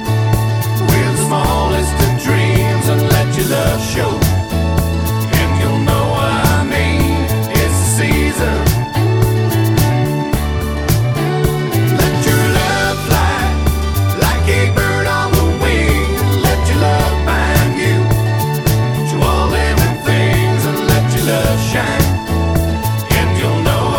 One Semitone Down Country (Male) 3:13 Buy £1.50